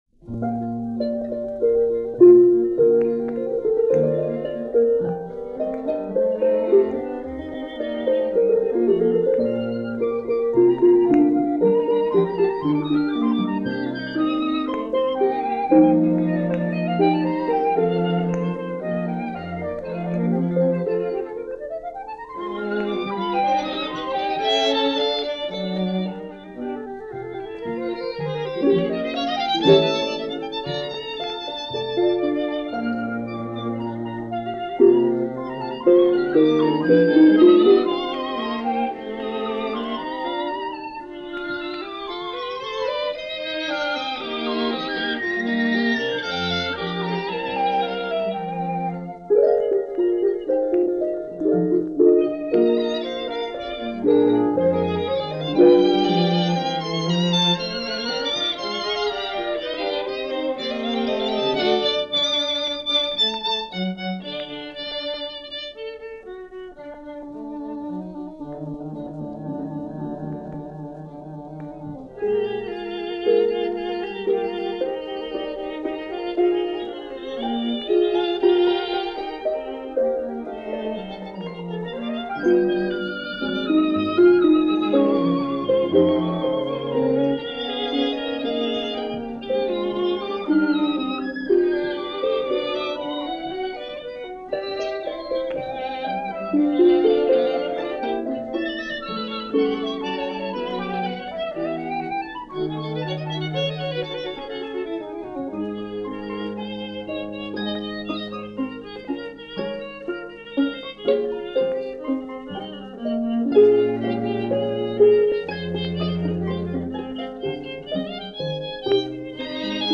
Pierre Jamet String Quartet play music of Charles Koechlin - The Charles Koechlin Memorial Concert - broadcast by ORTF-Paris.
Charles Koechlin – Memorial Concert – 1952 – ORTF – Radio Paris – Gordon Skene Sound Collection –
Tonight it’s a performance of his Primavera op. 156 for Fute, Harp and String Trio, featuring the Pierre Jamet String Quartet.